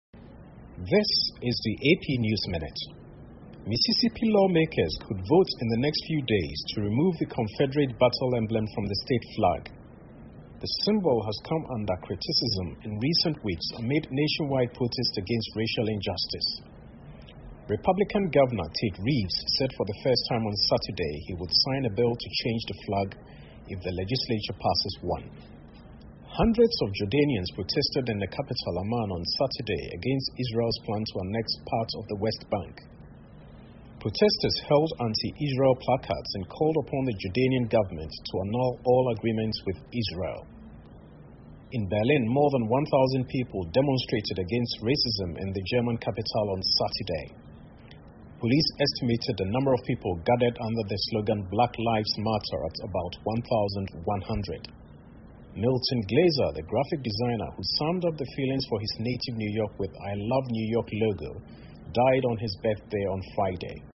美联社新闻一分钟 AP 联邦法院:美国密西西比州将重新设计州旗 听力文件下载—在线英语听力室